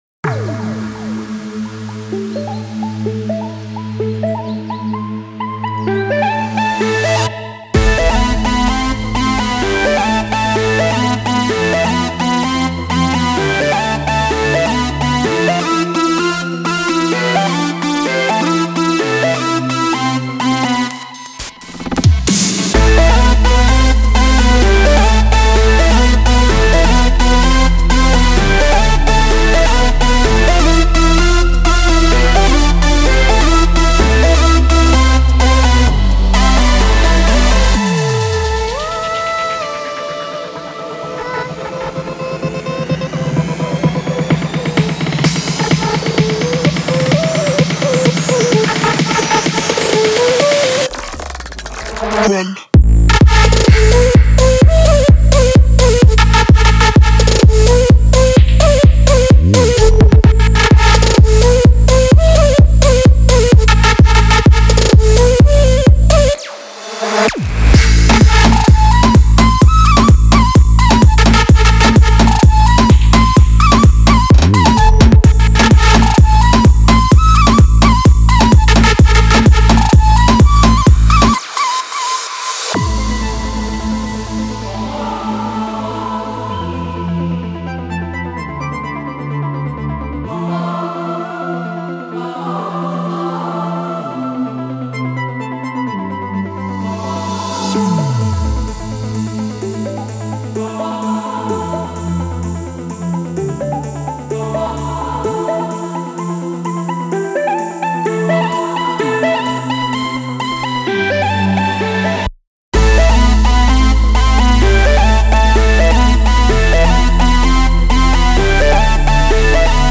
• Жанр: Electronic, EDM